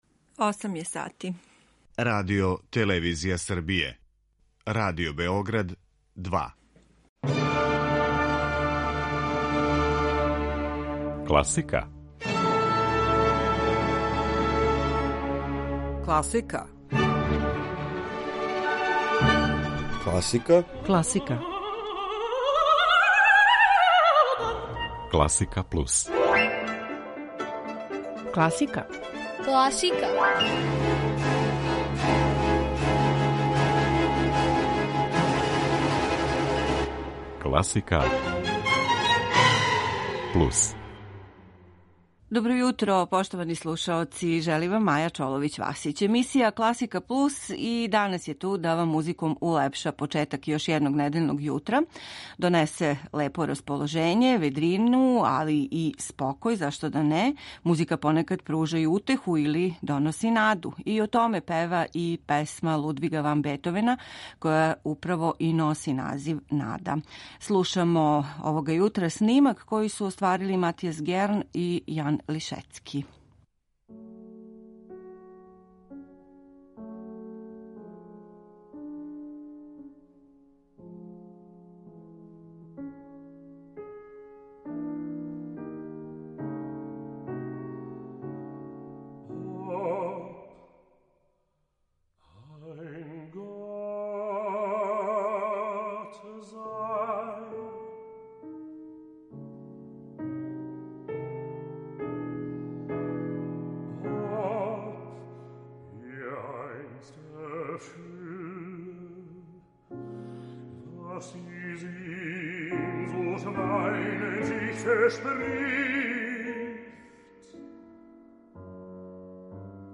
Пијаниста